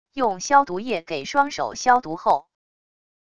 用消毒液给双手消毒后wav音频